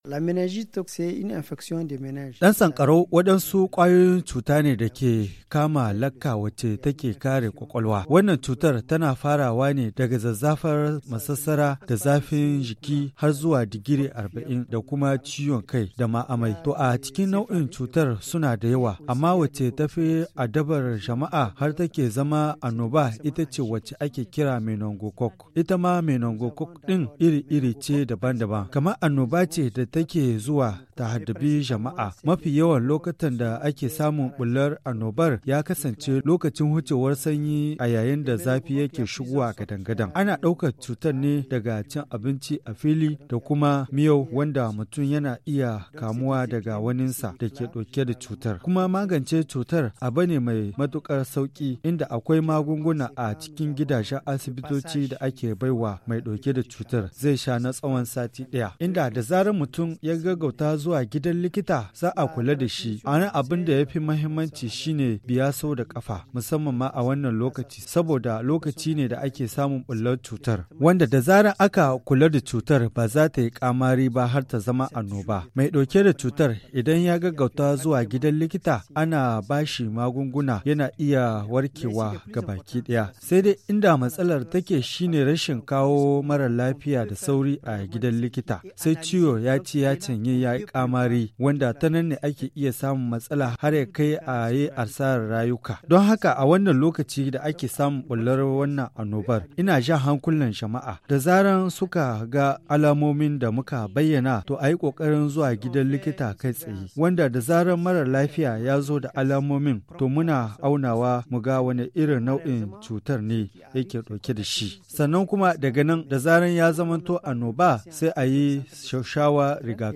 Magazine en haoussa